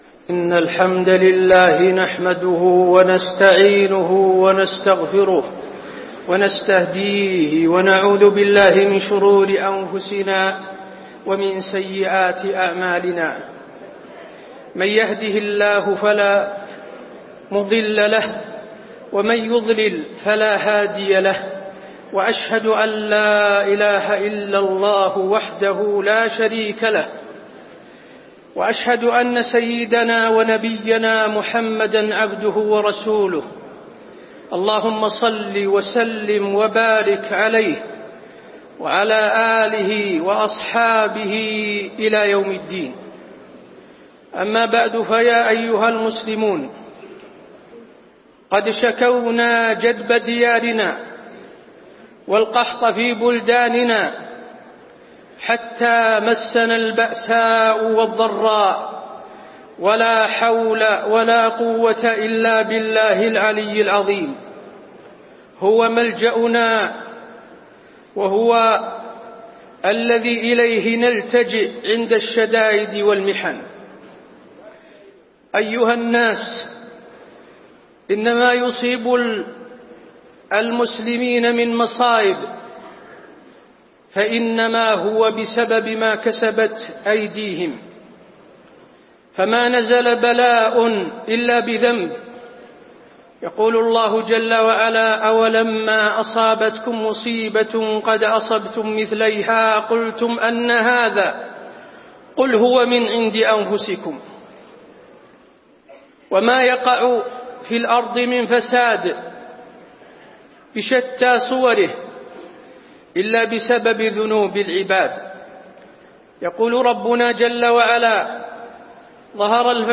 خطبة الاستسقاء - المدينة- الشيخ حسين آل الشيخ - الموقع الرسمي لرئاسة الشؤون الدينية بالمسجد النبوي والمسجد الحرام
المكان: المسجد النبوي